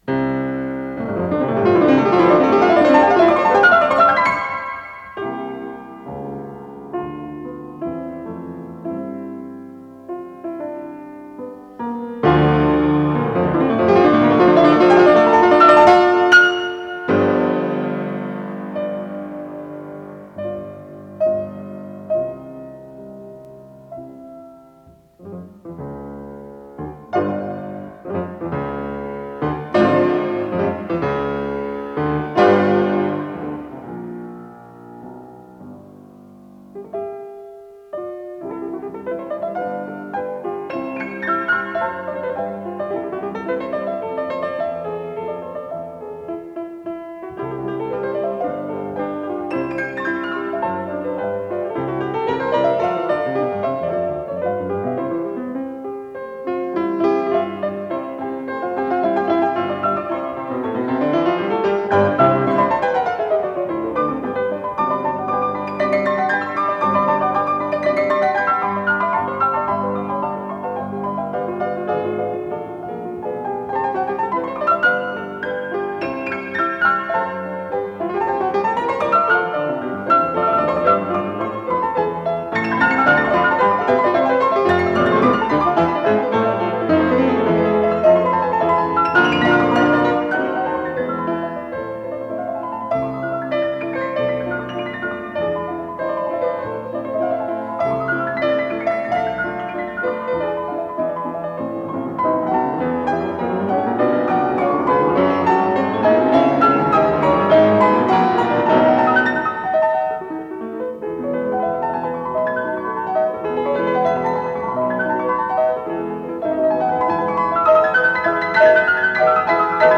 с профессиональной магнитной ленты
До мажор
фортепиано
ВариантДубль моно